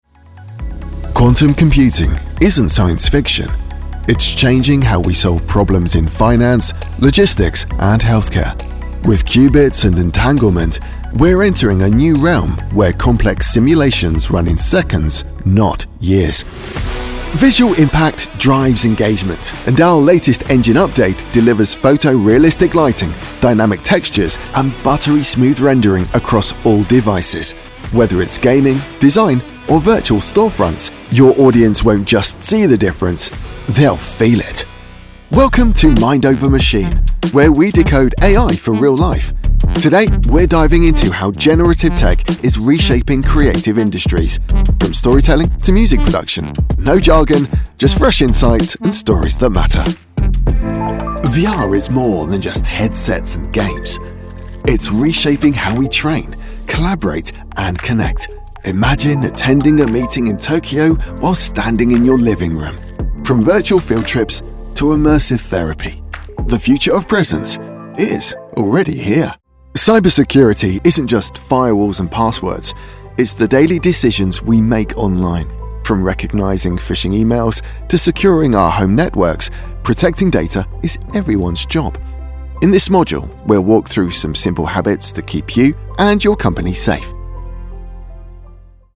Narração
Meu tom amigável e pé no chão, que cai na faixa baixa a média, conecta-se efetivamente com o público de uma maneira coloquial que é envolvente e acessível.
Microfone Neumann TLM193